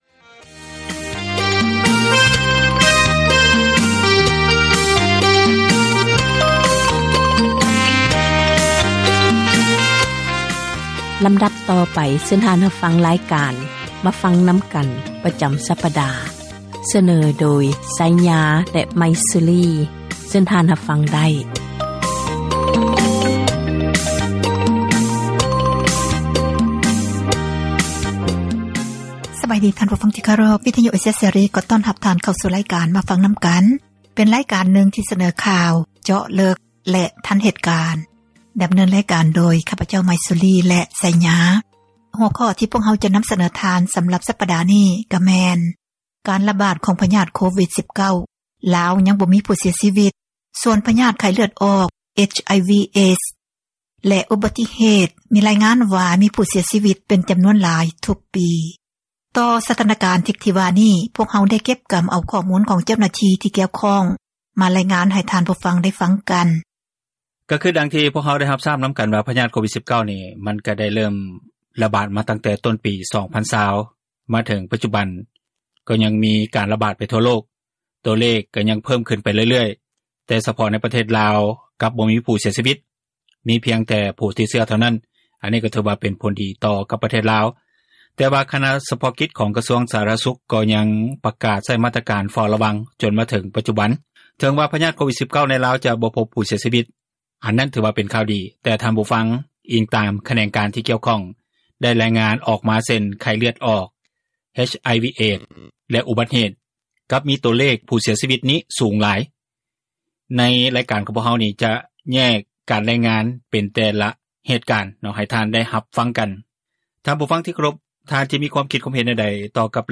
"ມາຟັງນຳກັນ" ແມ່ນຣາຍການສົນທະນາ ບັນຫາສັງຄົມ